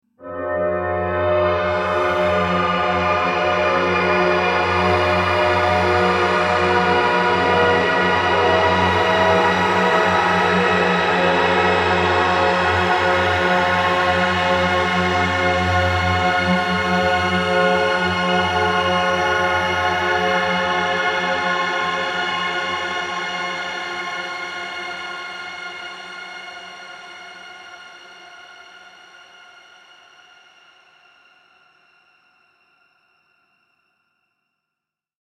Mystical Futuristic Synth Atmosphere
Immerse yourself in a mystical futuristic synth atmosphere with eerie sounds perfect for trailers and cinematic projects.
Eerie and mysterious transition or intro with a sci-fi dystopian atmosphere.
Scary sounds.
Mystical-futuristic-synth-atmosphere.mp3